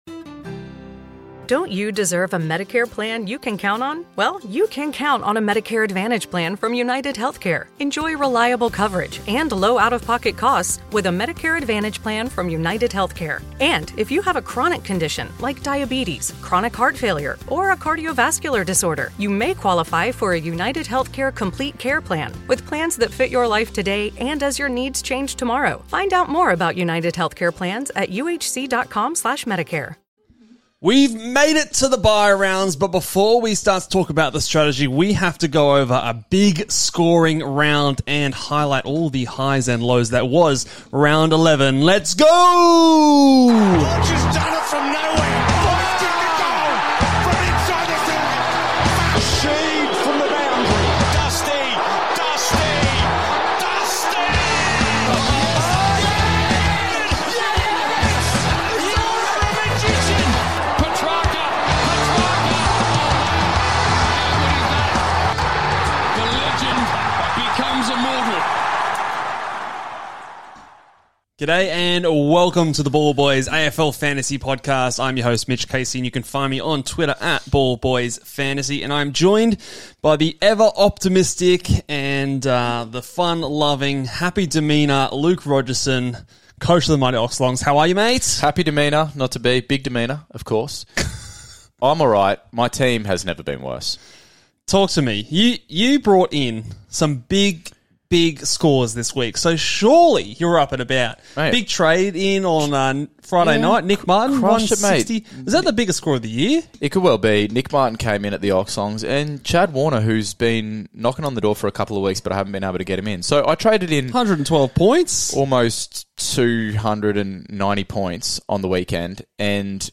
Round 7 LIVE: Captains & Trade Targets – Ball Boys AFL Fantasy Podcast – Podcast